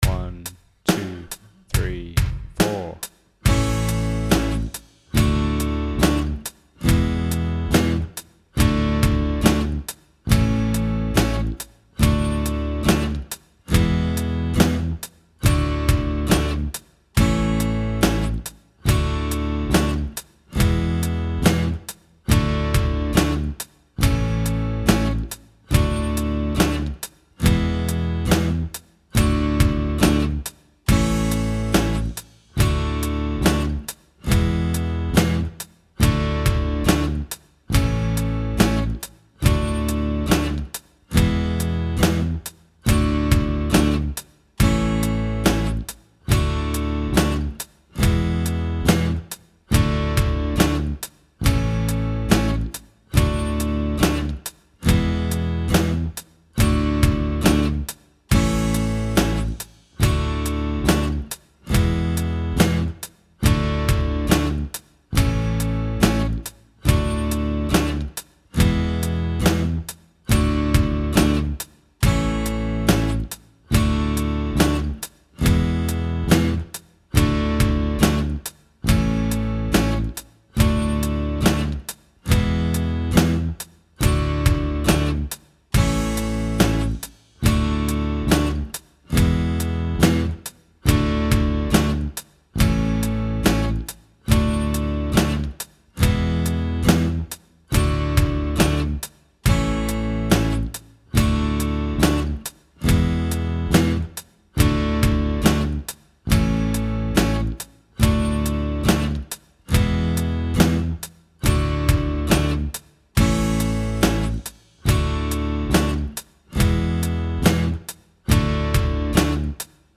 Wild Tune Backing Track | Download